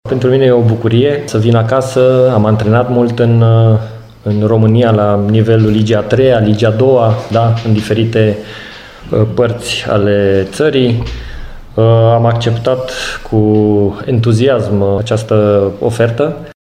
Tehnicianul s-a arătat încântat de propunerea primită şi pentru faptul că se întoarce acasă, unde a deprins tainele fotbalului: